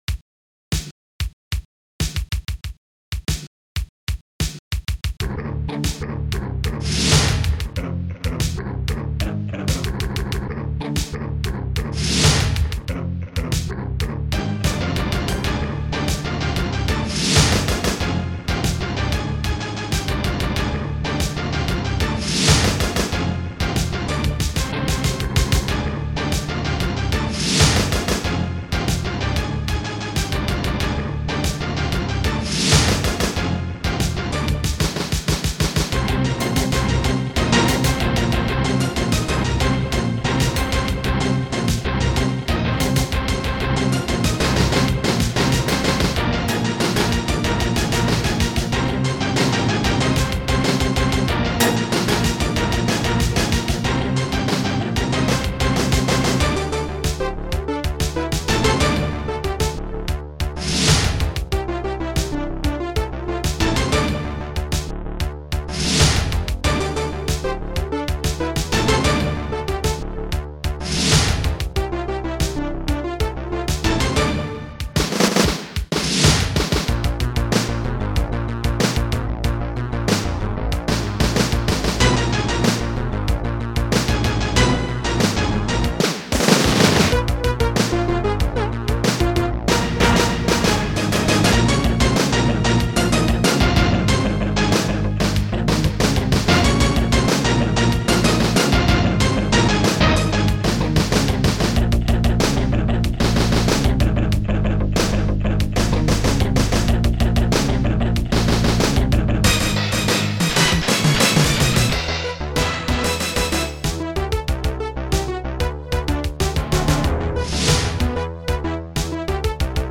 AMOS Music Bank
st-02:bassdrum5
st-02:snaredrum8
st-01:korgbass
st-02:cymbal1
st-02:scratch-it
st-01:hallbrass
st-03:big-drum2
st-01:PopBass
st-05:snare-two
st-02:p-bongo